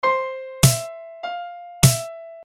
Tag: 100 bpm Hip Hop Loops Drum Loops 413.61 KB wav Key : Unknown